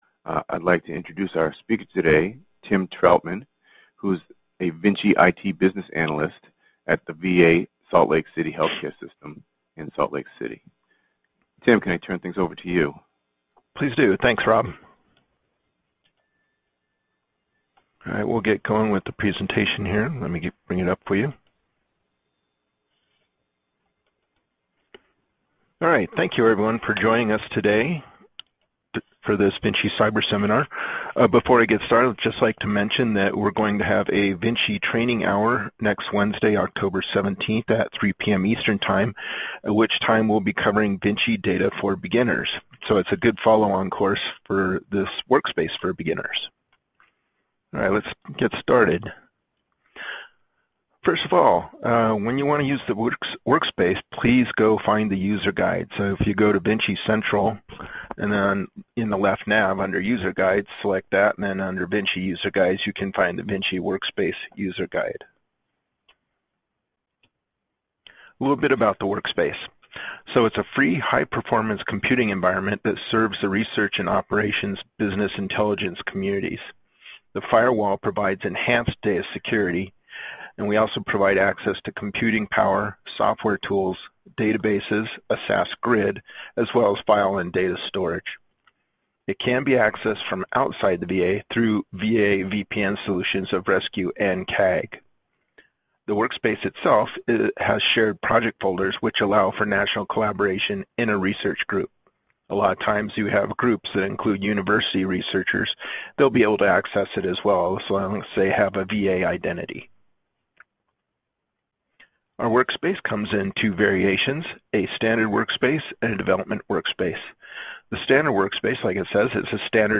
Intended Audience: This cyberseminar is for beginning VINCI Workspace users and those potentially interested in using the workspace for research.